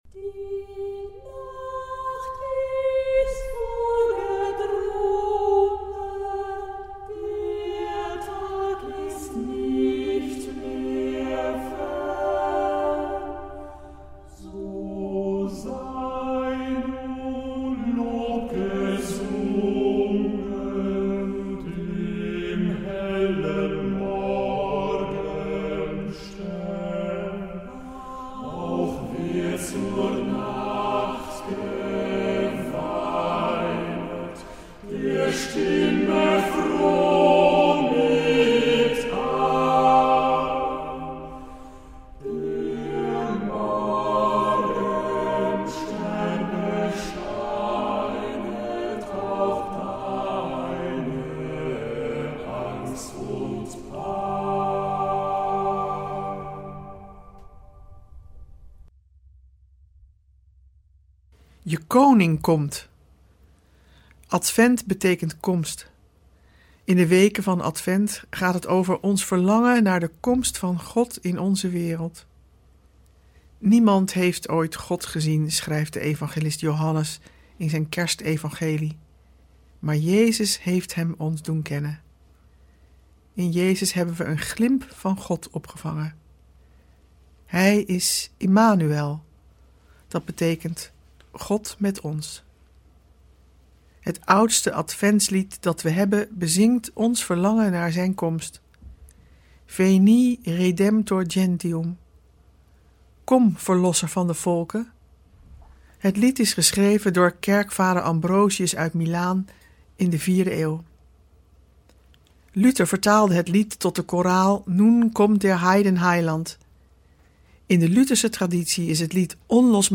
We verbinden ze met onze tijd en we luisteren naar prachtige muziek en poëzie.